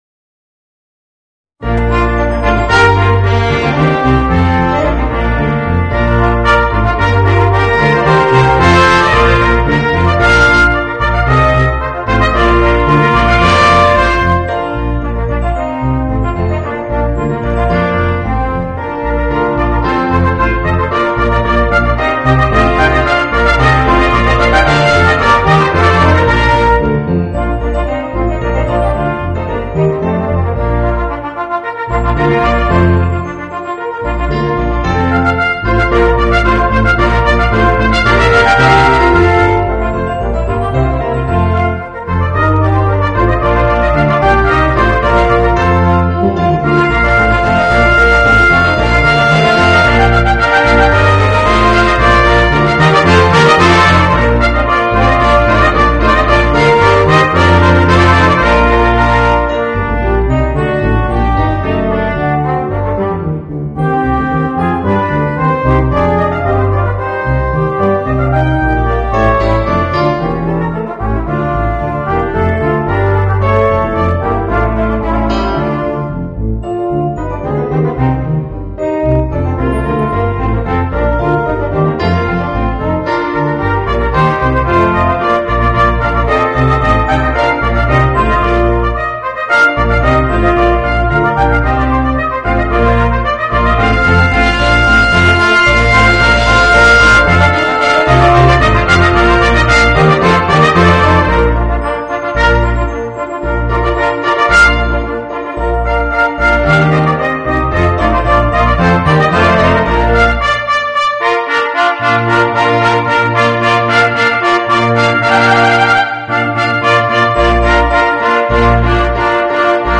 Voicing: 4 - Part Ensemble and Piano